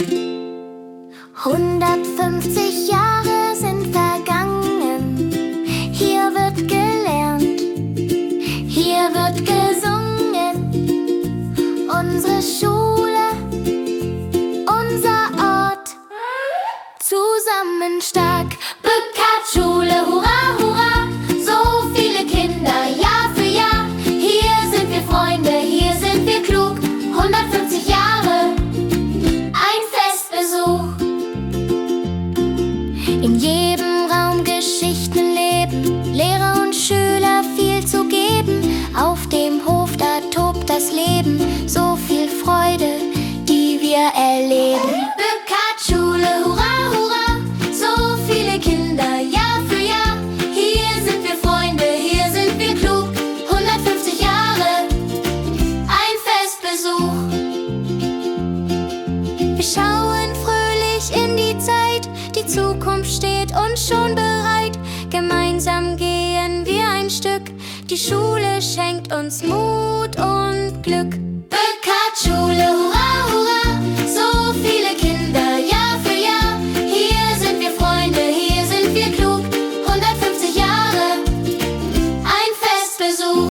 Zum Schuljubiläum 150 Jahre Bückardtschule in Bielefeld wurde eigens ein Lied komponiert.